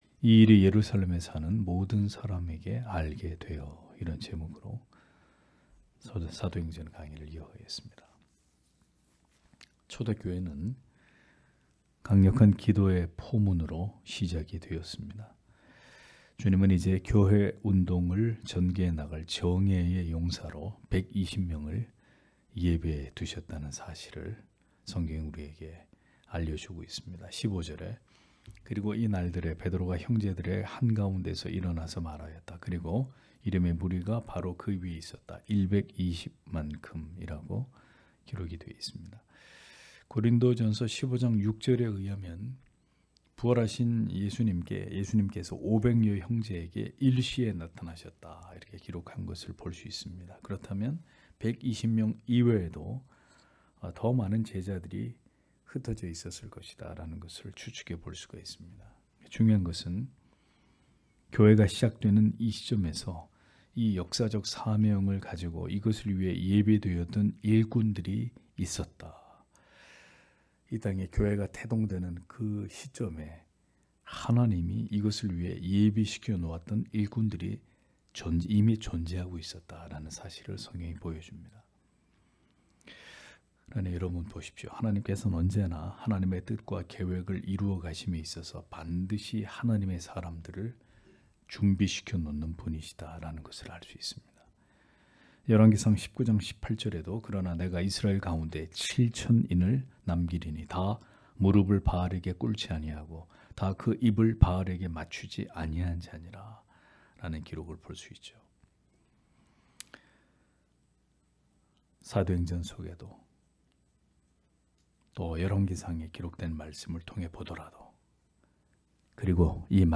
금요기도회 - [사도행전 강해 06] 이 일이 예루살렘에 사는 모든 사람에게 알게 되어 (행 1장 15- 19절)